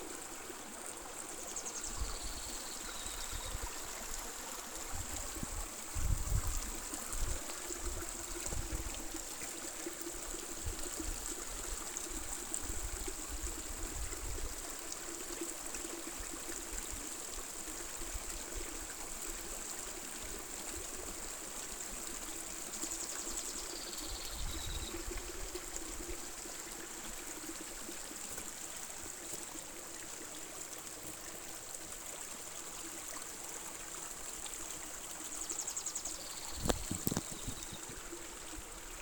Jilguero Oliváceo (Sicalis olivascens)
Nombre en inglés: Greenish Yellow Finch
Localidad o área protegida: Amaicha del Valle
Condición: Silvestre
Certeza: Vocalización Grabada